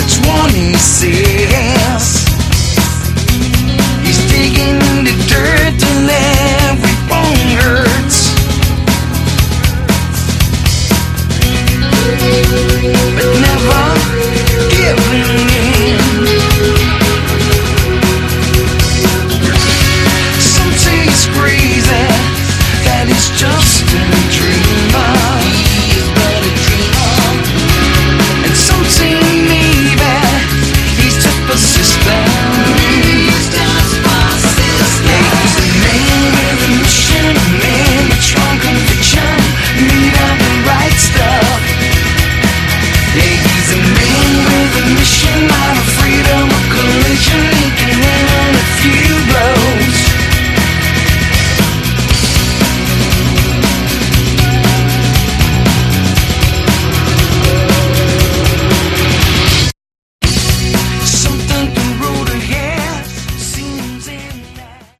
Category: AOR
lead vocals, guitar
drums